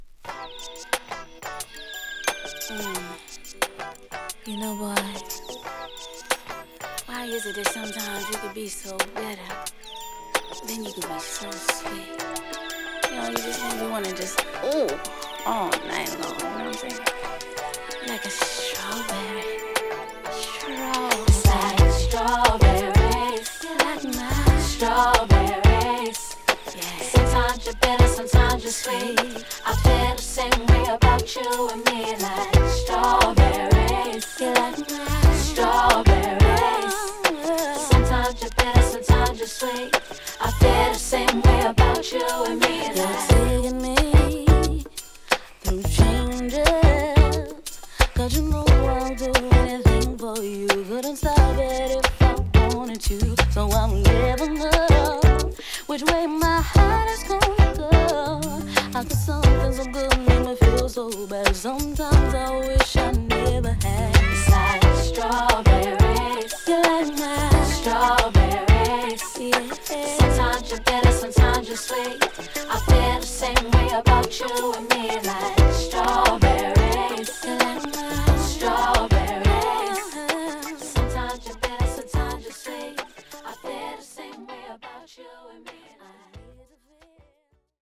キャッチ―と洗練された90'S R&Bのスイング感が絶妙なナンバー!